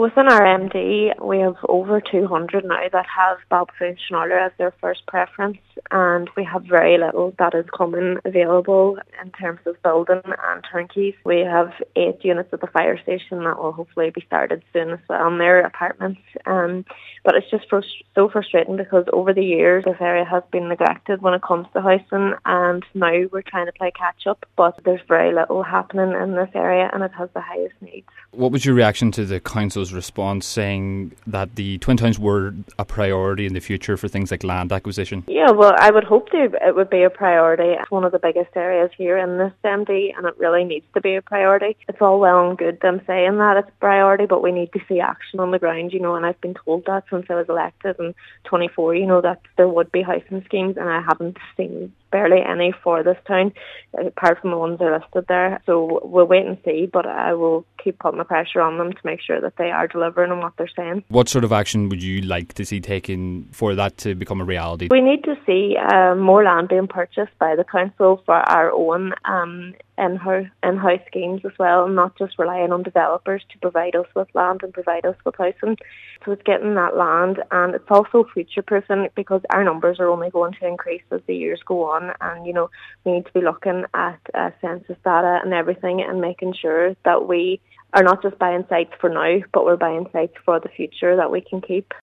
Cllr Dakota NicMheanmain told a Lifford Stranorlar Municipal District meeting that there’s been a recent history of under-provision of social homes in the MD, and while 14 turnkey homes have been acquired for the area, the Twin Towns are still very much playing catch-up.